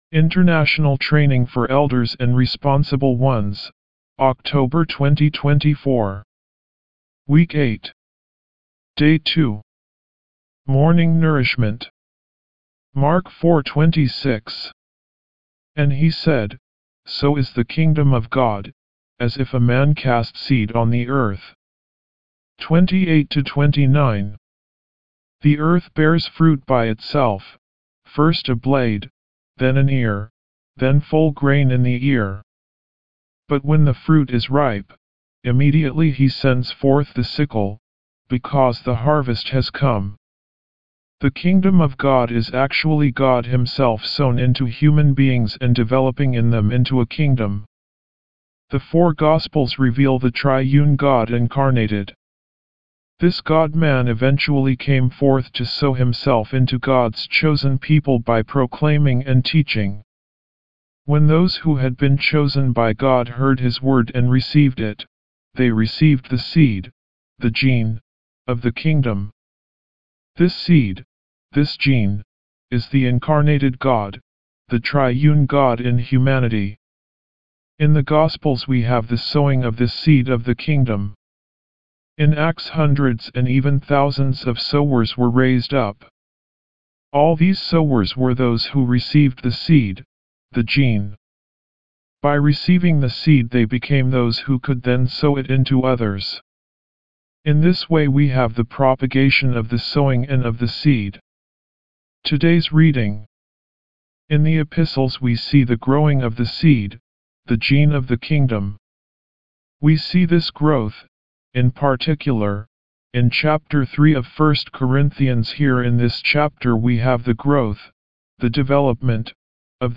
D2 English Rcite：